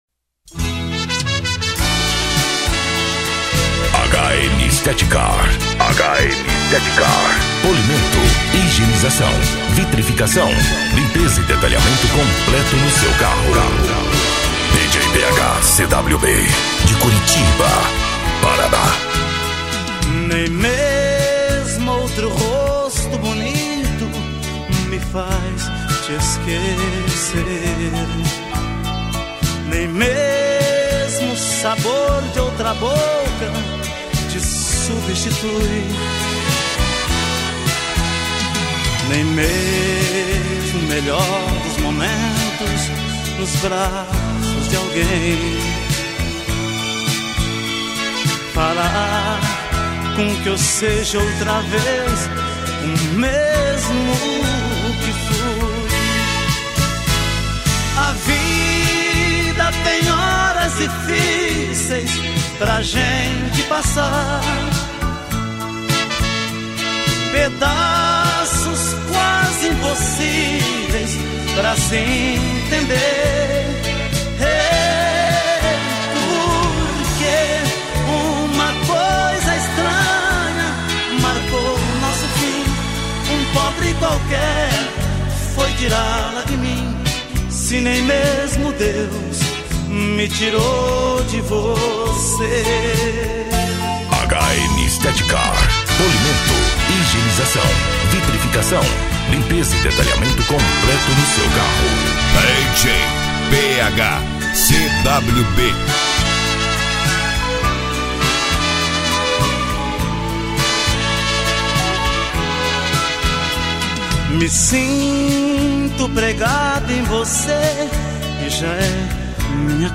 Eletronica
SERTANEJO